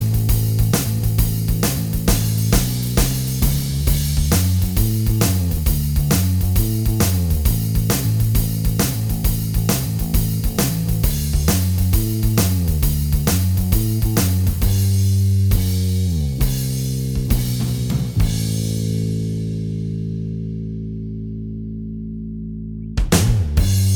Down One Semitone Rock 3:43 Buy £1.50